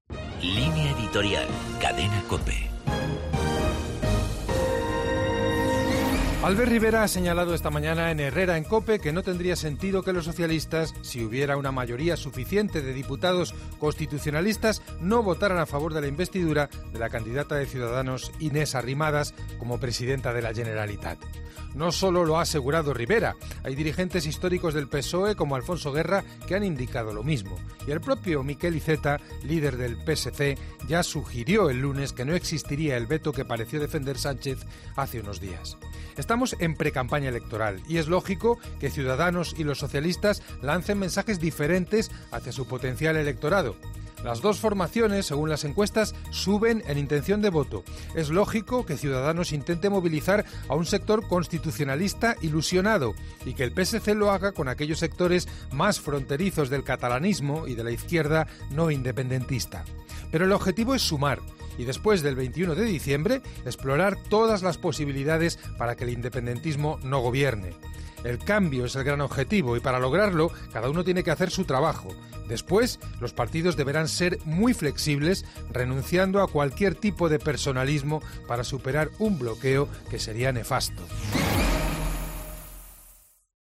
Línea editorial de la Cadena COPE